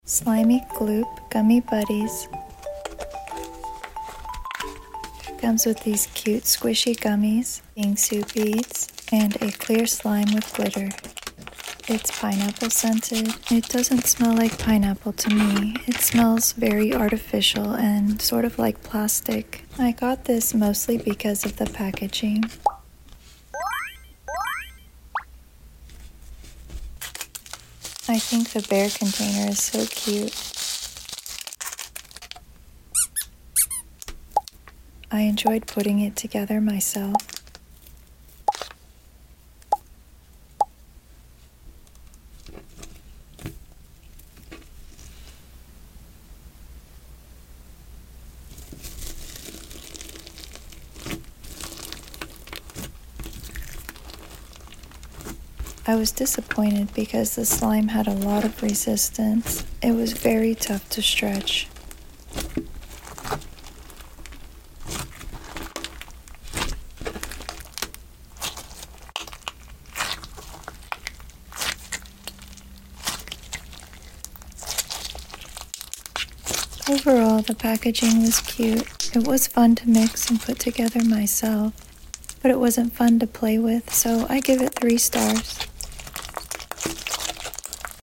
✨🧸 SlimyGloop Gummy Buddies Store bought slime review ASMR.